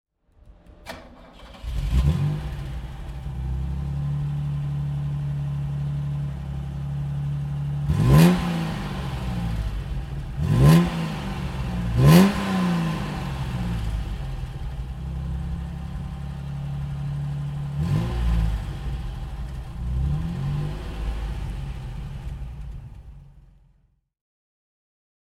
Opel Monza GSE (1983) - Starten und Leerlauf